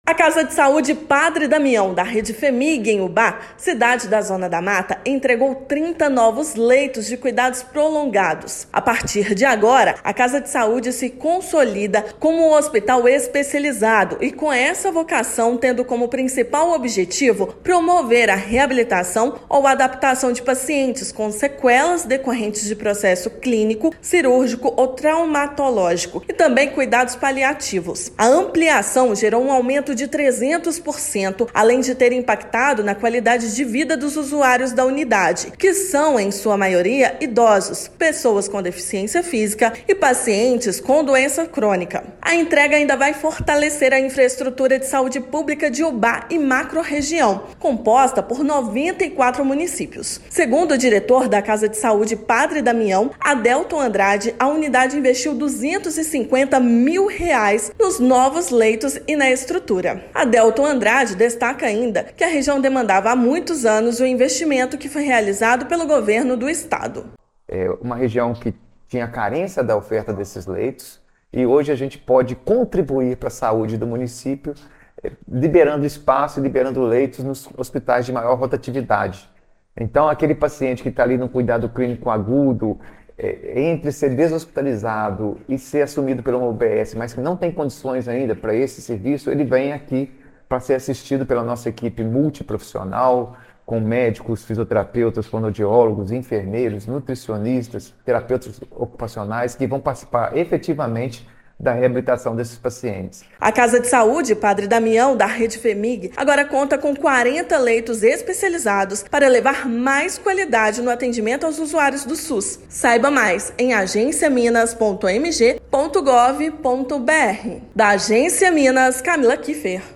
[RÁDIO] Governo de Minas amplia em 300% a capacidade de atendimento a pacientes que necessitam de cuidados prolongados em Ubá e região
Casa de Saúde Padre Damião, da Rede Fhemig, agora conta com 40 leitos especializados para levar mais qualidade no atendimento aos usuários do SUS. Ouça matéria de rádio.